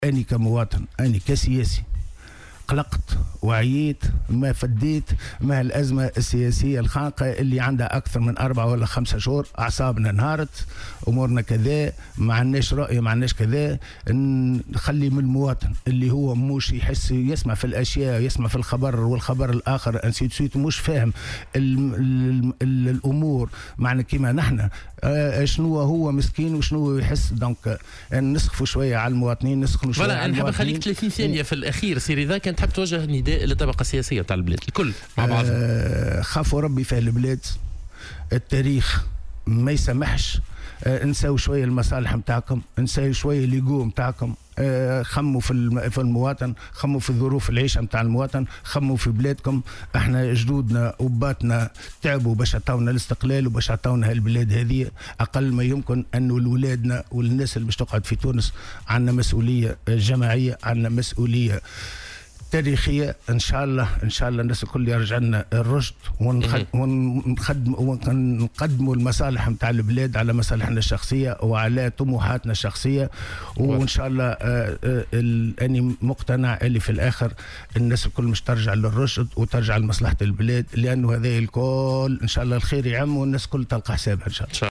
وحمّل في مداخلة له اليوم في برنامج "صباح الورد" المسؤولية لجميع الأحزاب، داعيا إياها إلى العودة إلى طاولة الحوار والبحث عن الحلول الكفيلة بحلحلة الأزمة والخروج من هذا الوضع الصعب.